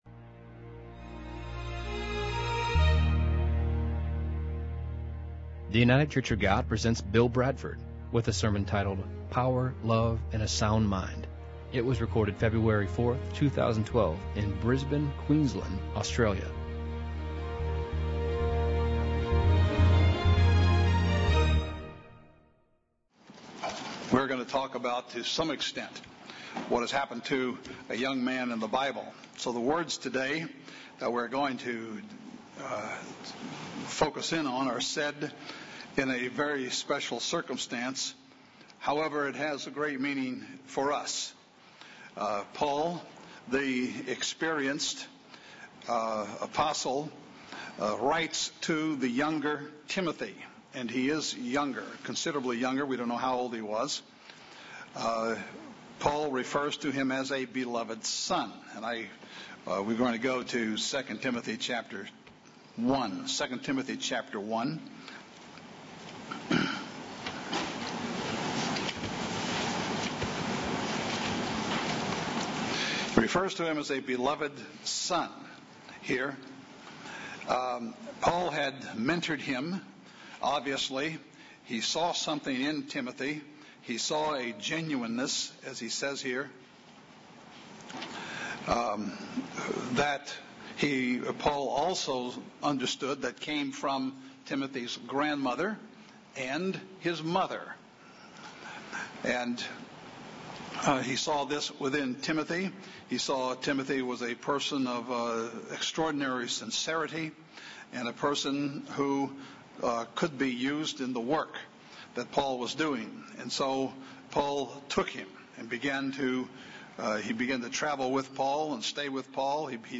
This sermon focuses on the words that Paul wrote to Timothy that has great meaning for us today.